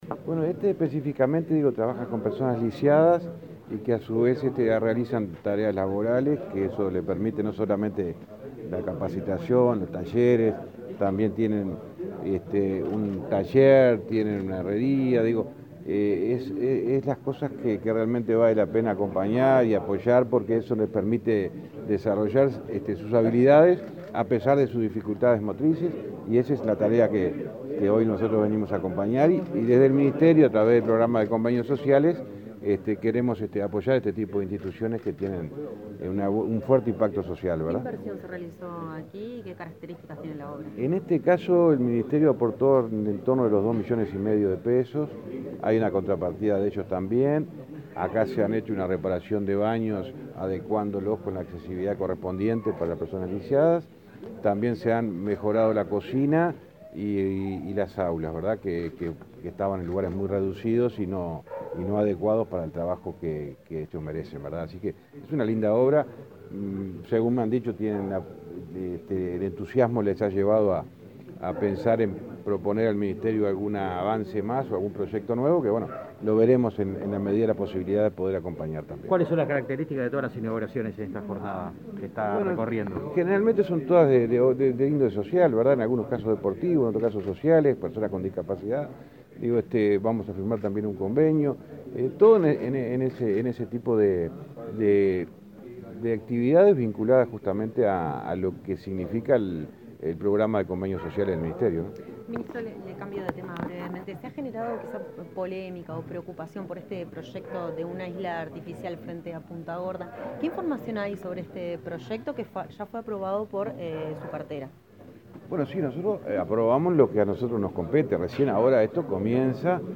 Declaraciones del ministro de Transporte, José Luis Falero
Luego de visitar las de la Organización Nacional Pro Laboral para Lisiados (Onpli) dialogó con la prensa.